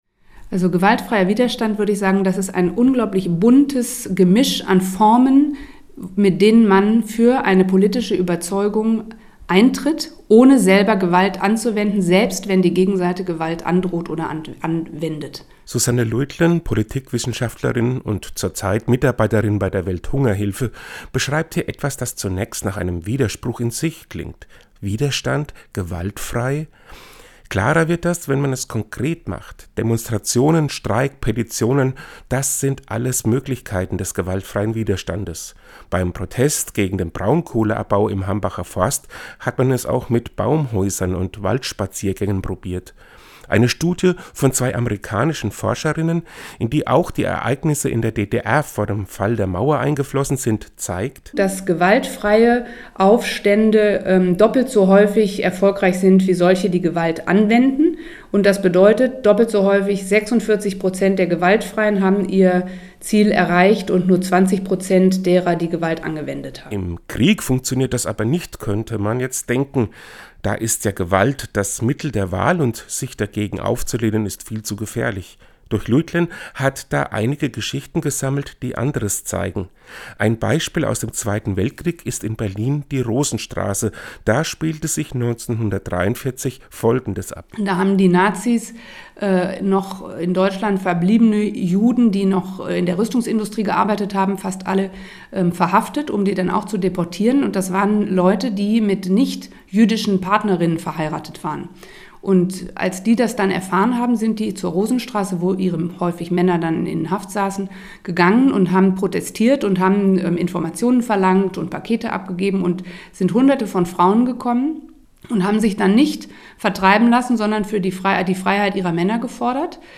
Den Radiobeitrag finden Sie unten als Download!